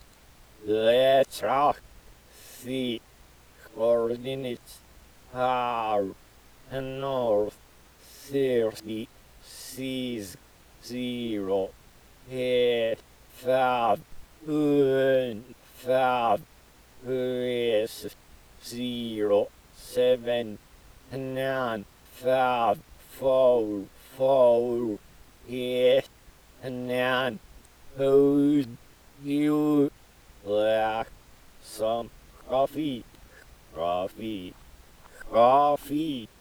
To reach the "Let's Rock" cache, you had to listen to a recording of the Little Man From Another Place giving you the coordinates.